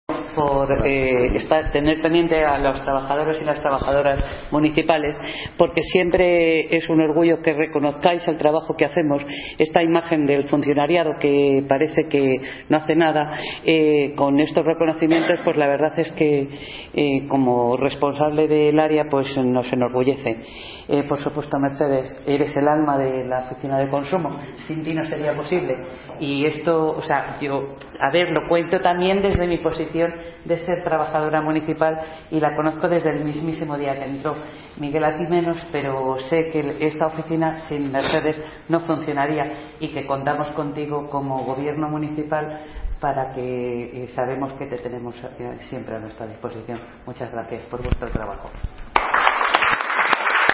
Audio - Beatriz Benavides (Concejala de Igualdad y Cooperación) Sobre Reconocimiento AAVV El Recreo